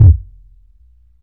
• Boom Steel Kick Drum Sample E Key 112.wav
Royality free kickdrum tuned to the E note. Loudest frequency: 95Hz
boom-steel-kick-drum-sample-e-key-112-mtm.wav